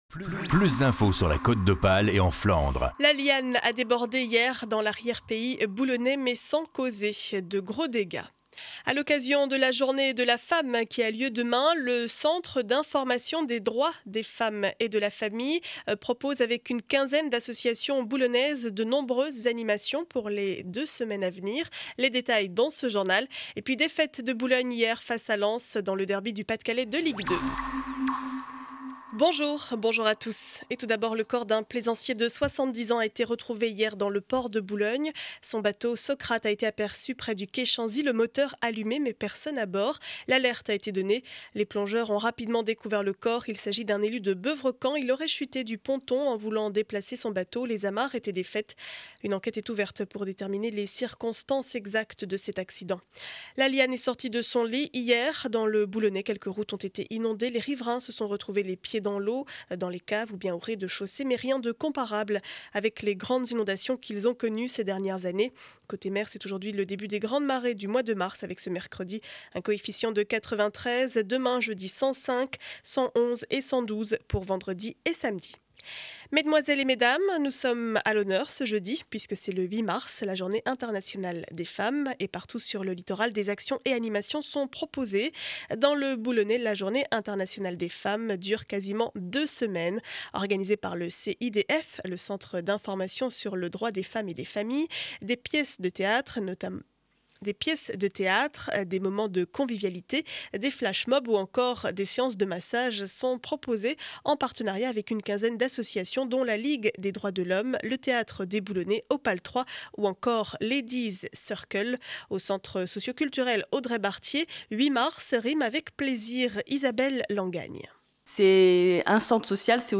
Journal du mercredi 07 mars 2012 7 heures 30 édition du Boulonnais.